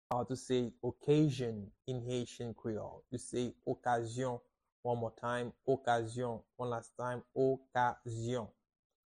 How to say "Occasion" in Haitian Creole - "Okazyon" pronunciation by a native Haitian tutor
“Okazyon” Pronunciation in Haitian Creole by a native Haitian can be heard in the audio here or in the video below:
How-to-say-Occasion-in-Haitian-Creole-Okazyon-pronunciation-by-a-native-Haitian-tutor.mp3